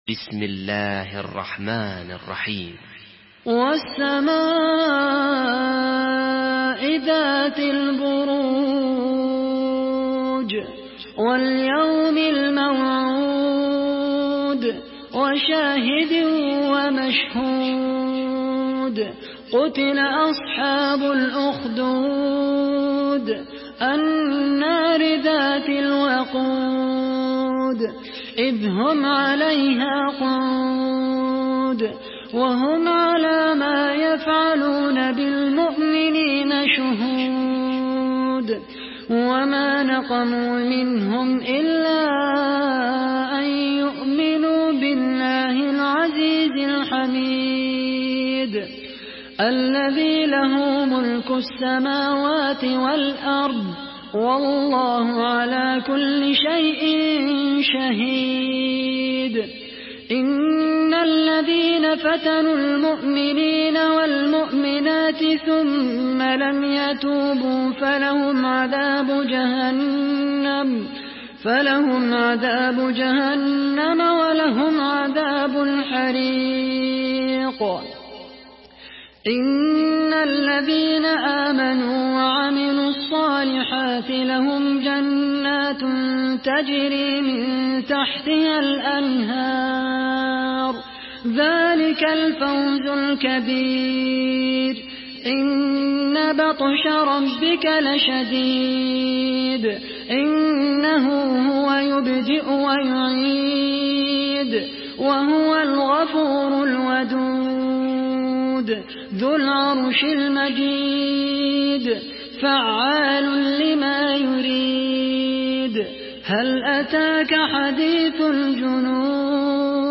Surah Al-Buruj MP3 by Khaled Al Qahtani in Hafs An Asim narration.
Murattal